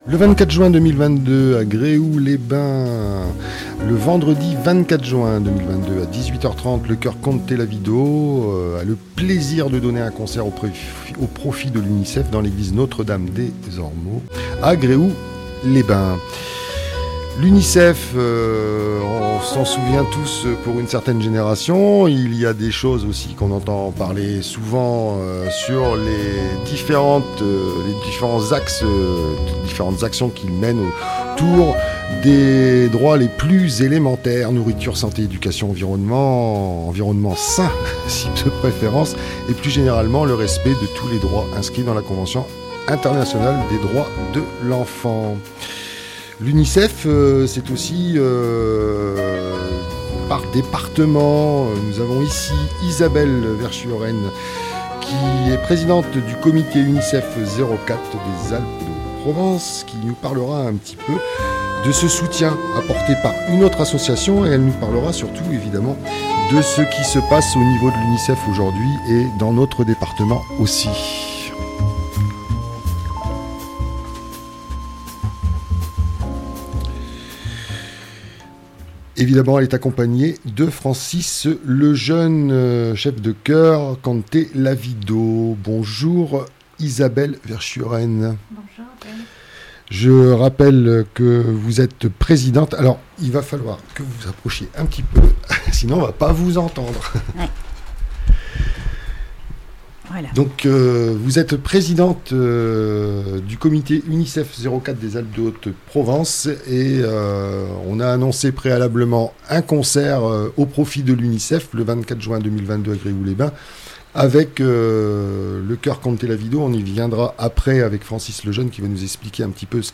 venus nous présenter l'événement au micro